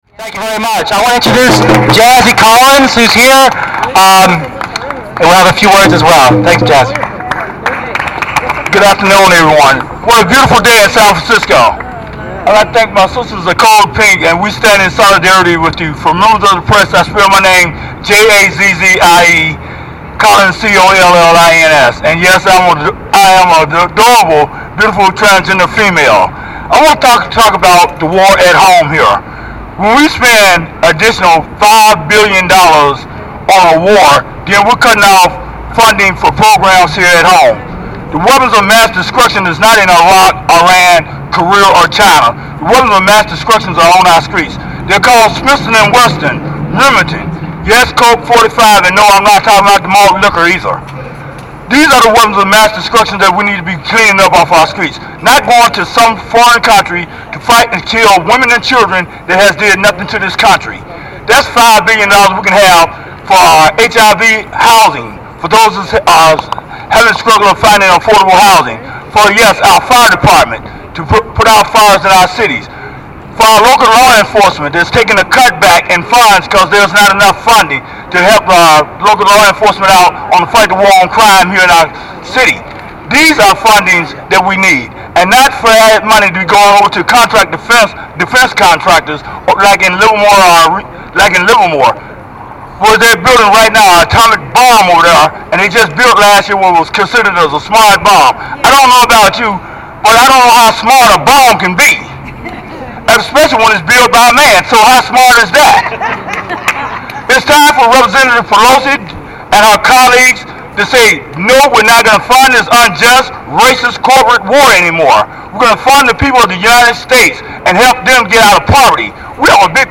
Meeting at the foot of San Francisco's Federal Building this morning, San Francisco Supervisors Ross Mirkarimi and Chris Daly joined other speakers to demand that Congresswoman and Speaker of the U.S. House of Representatives Nancy Pelosi honor their resolution and the opinion of a majority of citizens to discontinue funding military operations and hold the Bush administration accountable for its total failure in Iraq.
Following are some more photos of speakers in chronological order with short mp3 recordings of their statements.